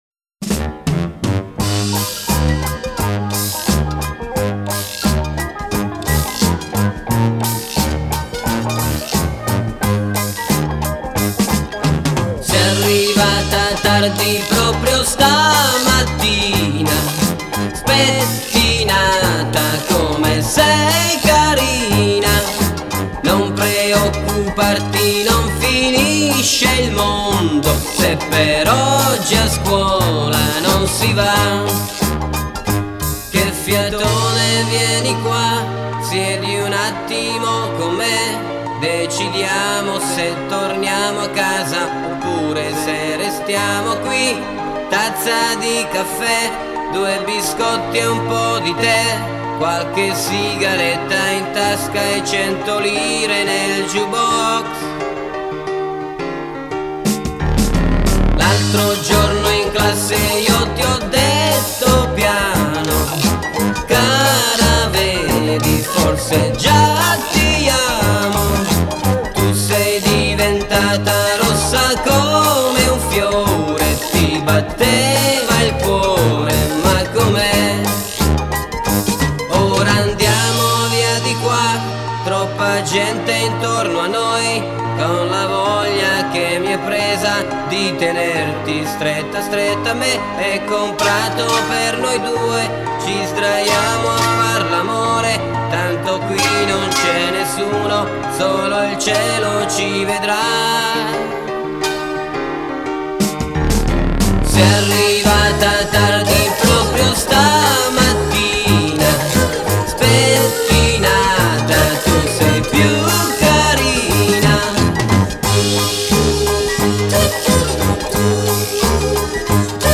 Итальянская эстрада
vocals, keyboard
guitar
drums
bass